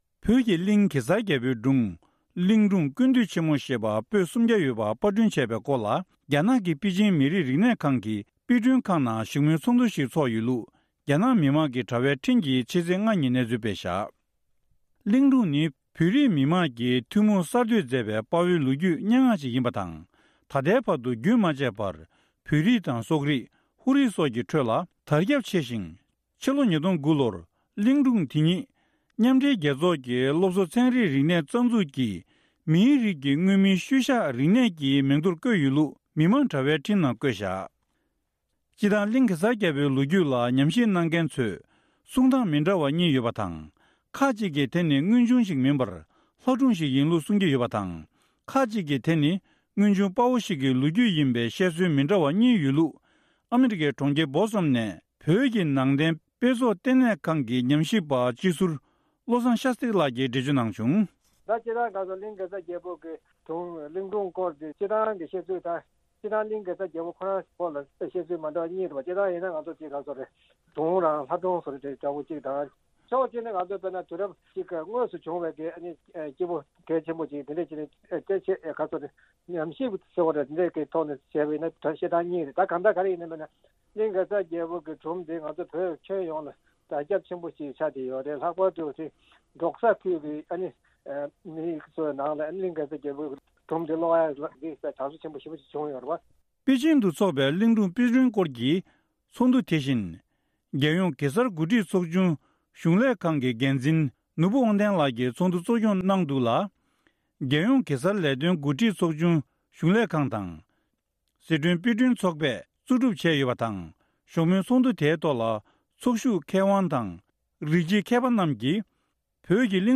བཀའ་འདྲི་ཞུས་ནས་ཕྱོགས་སྒྲིག་ཞུས་པ་ཞིག་གསན་རོགས་གནང་།།